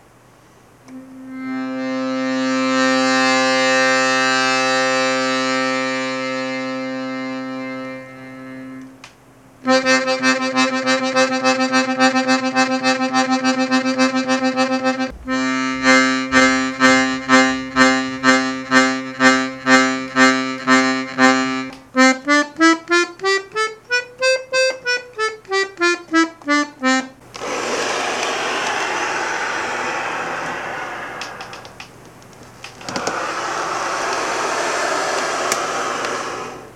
AccordionBellowsSounds.ogg